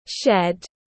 Nhà kho tiếng anh gọi là shed, phiên âm tiếng anh đọc là /ʃed/.